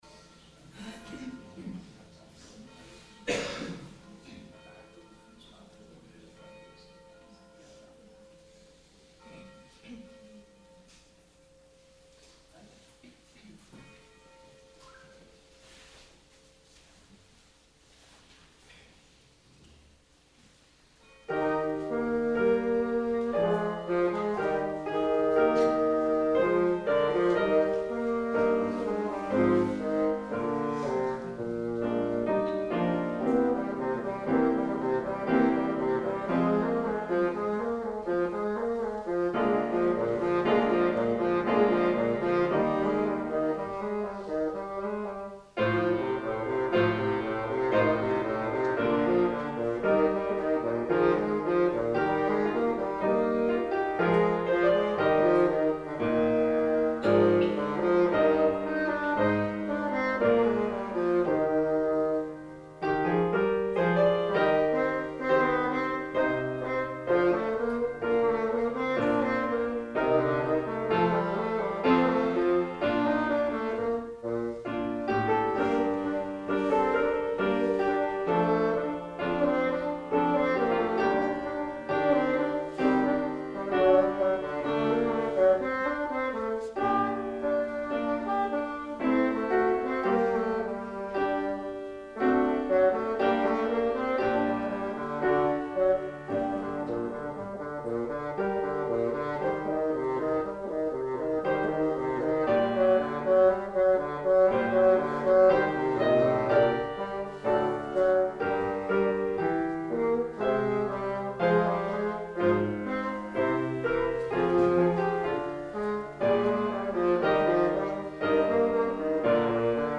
Gottesdienst - 08.03.2026 ~ Peter und Paul Gottesdienst-Podcast Podcast